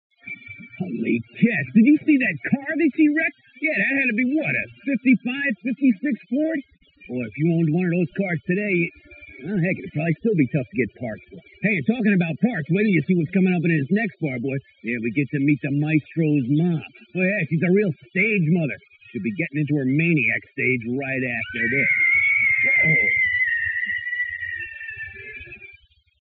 Not the greatest audio source. This is admittedly heavily processed, but almost all of the noise is gone.